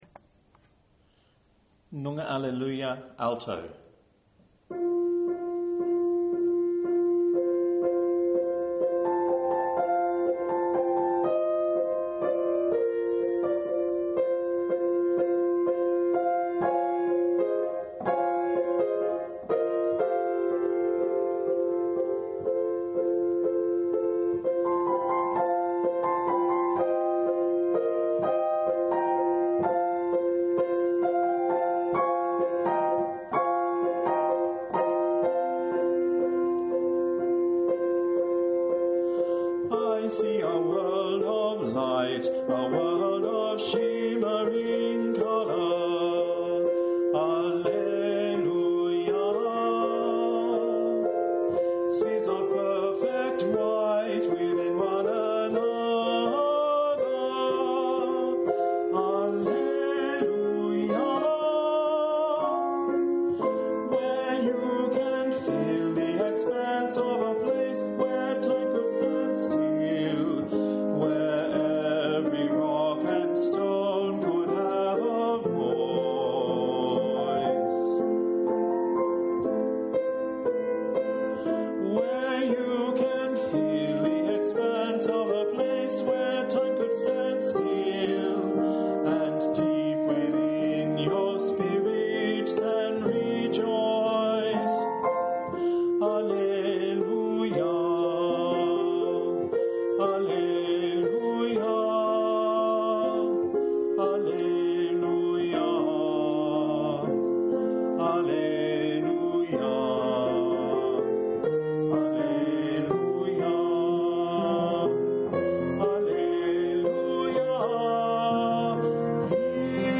7.-WALKER_NyungarAlleluia_ALTO.MP3